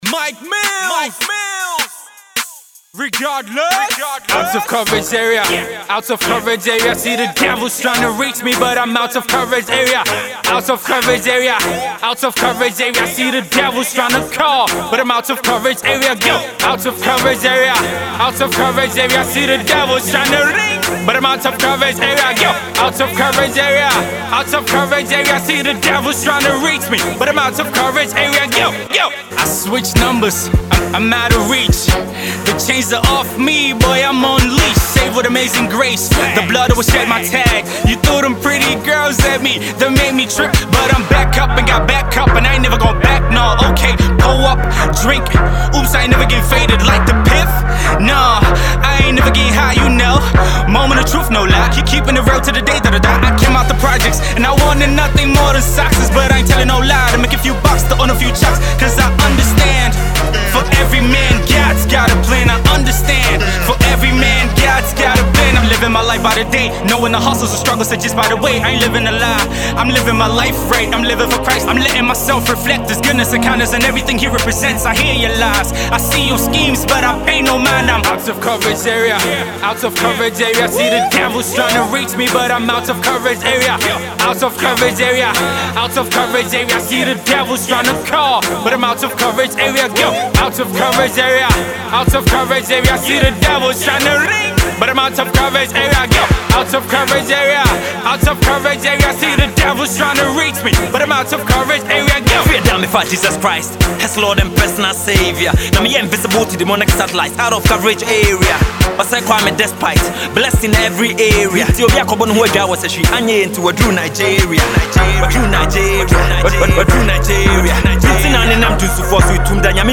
gospel rapper
Hip hop bars